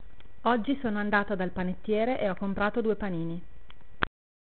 The volume is quite low, I hope you can hear something.
Is it just me or does my voice sound different when I speak in different languages? o_O